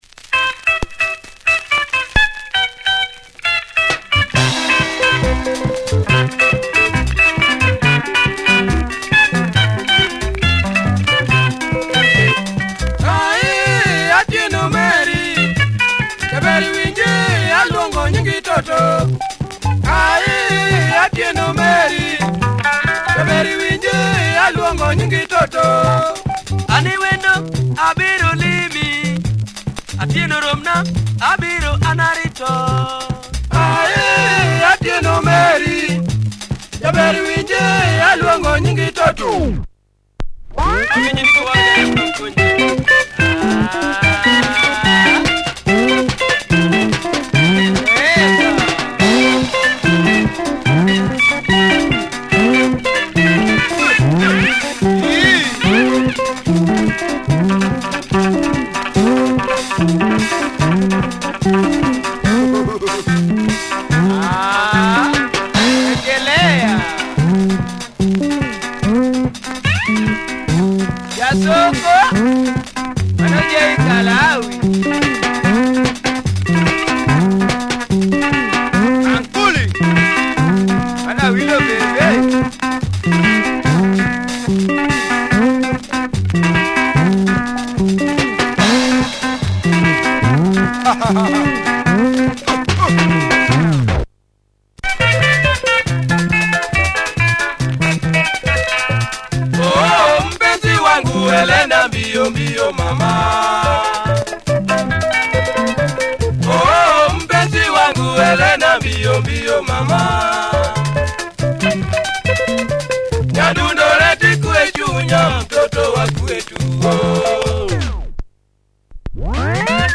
Benga
Some marks but plays fine, A side has some tear on label.